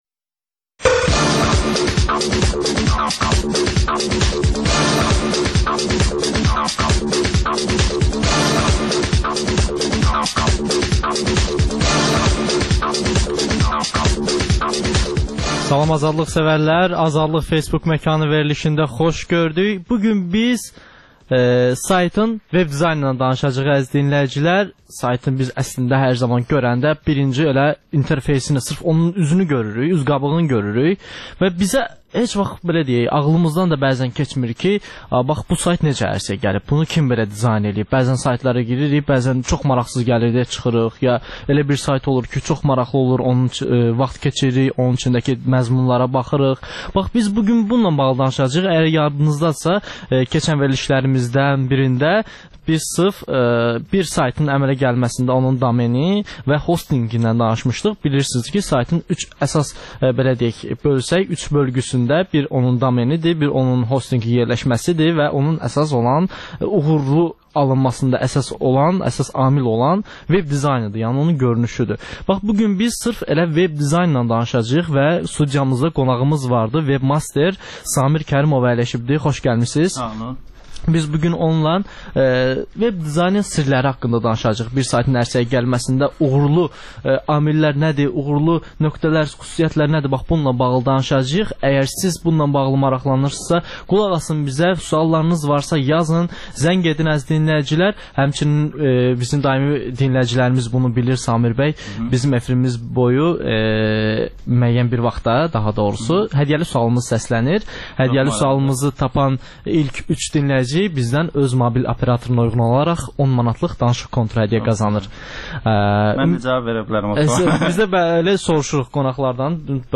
Azərbaycanda və dünyada baş verən ən son yeniliklər barədə operativ xəbər proqramı.